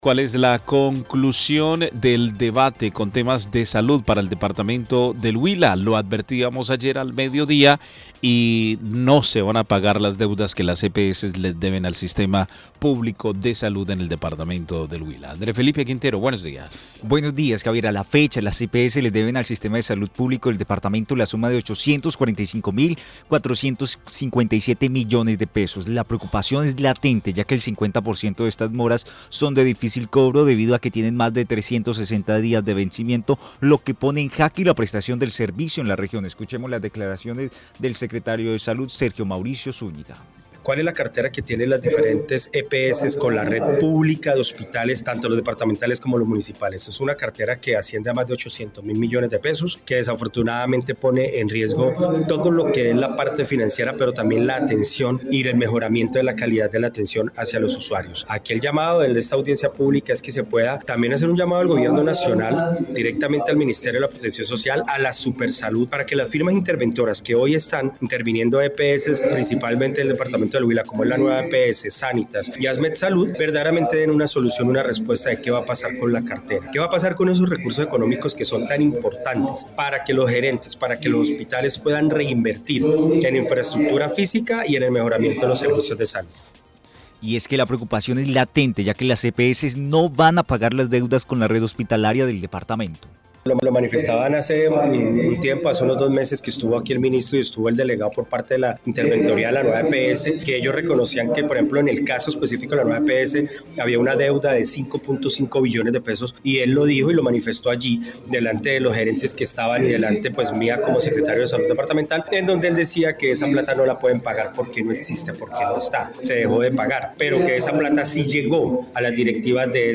Las EPS no van a pagar las deudas con la red hospitalaria del Huila , así lo dio a conocer el secretario de Salud departamental Sergio Mauricio Zúñiga. En el marco de la audiencia pública que se desarrolló en el recinto de la Asamblea, el funcionario dio a conocer la noticia.